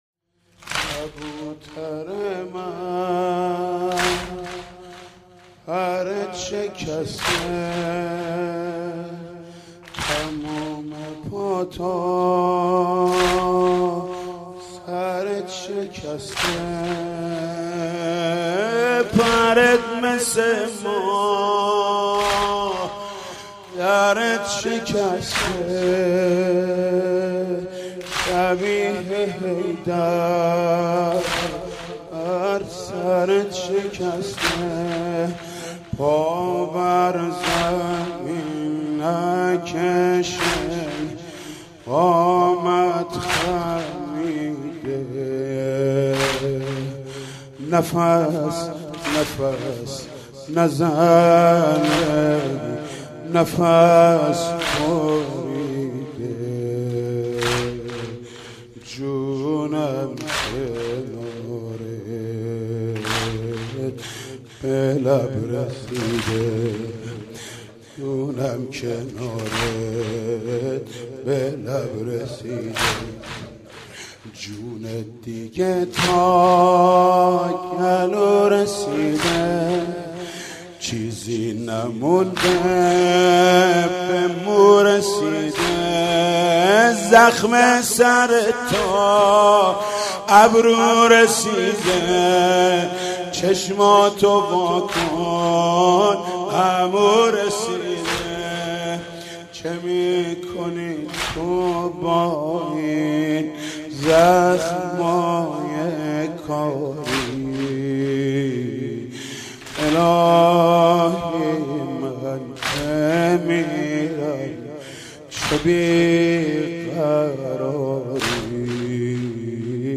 مجموعه مراسم محمود کریمی در شب ششم محرم 93